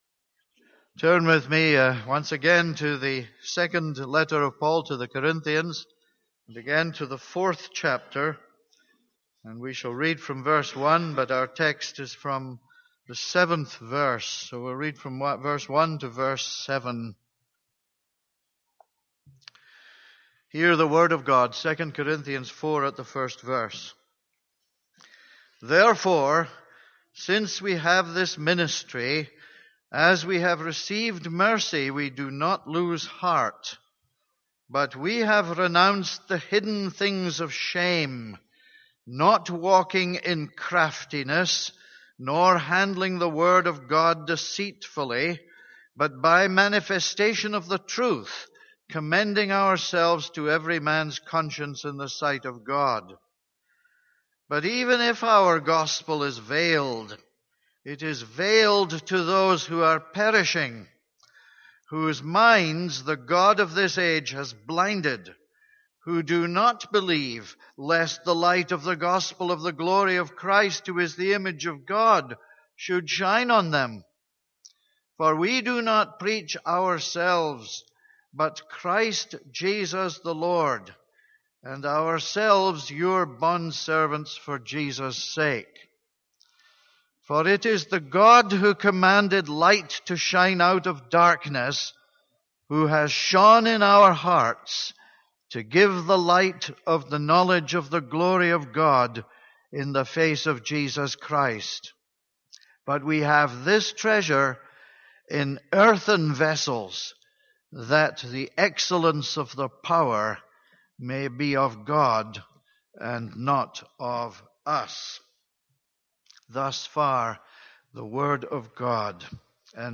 This is a sermon on 2 Corinthians 4:7.